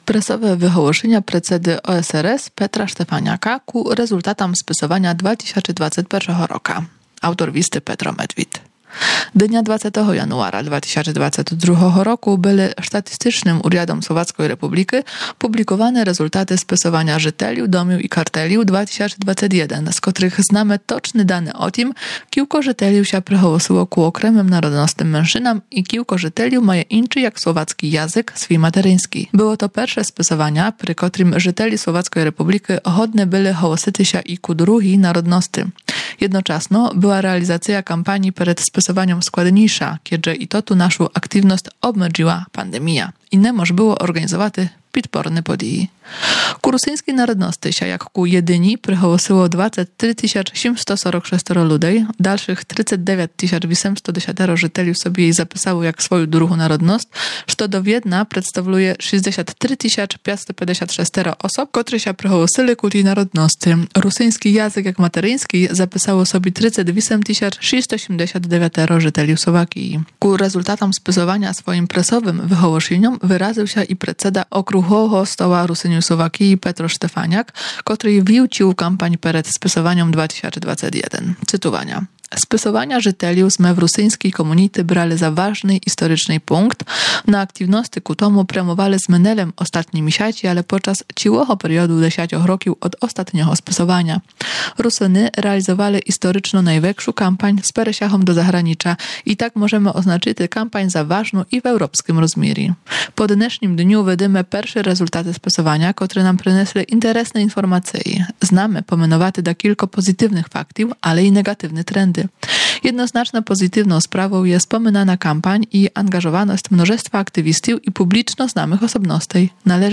Пресове выголошіня